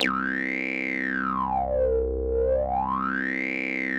C2_wasp_lead_1.wav